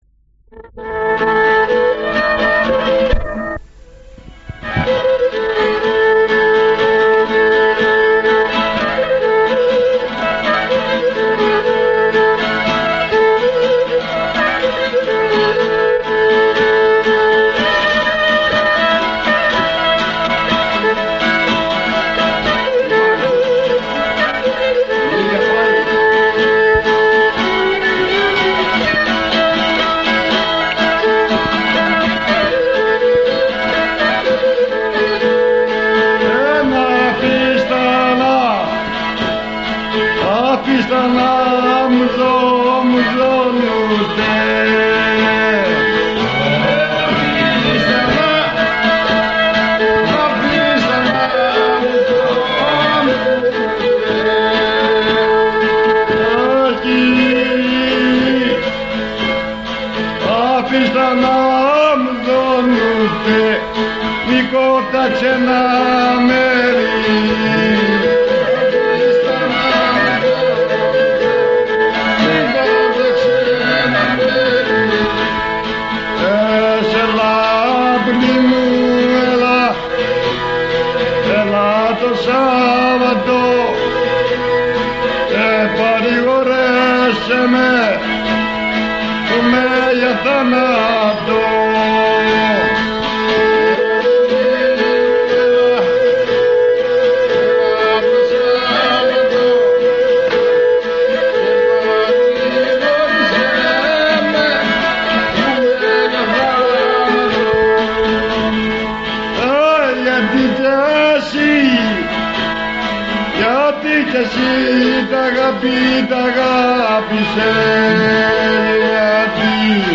Καταγραφή οργανοπαιχτών
στο Απέρι, γύρω στο 1963
Λύρα